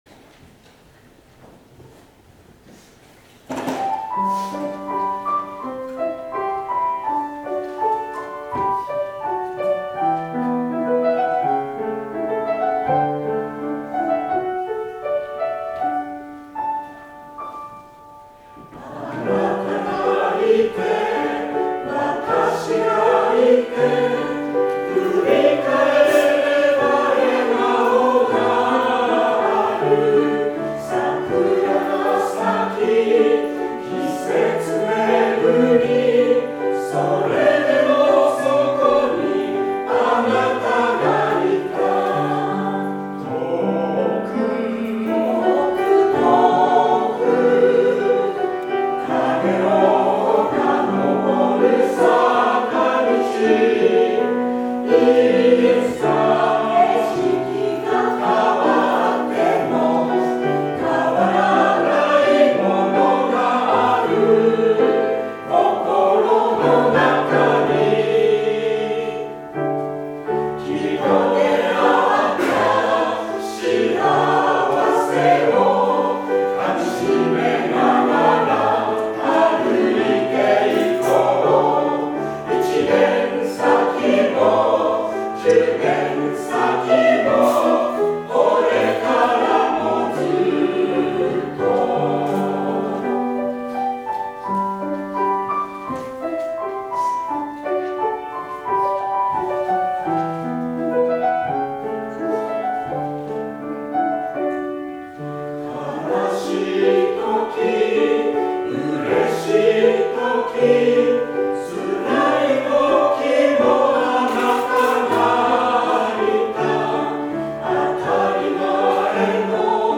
聖歌隊奉唱・献花
「変わらないもの」礼拝堂後部録音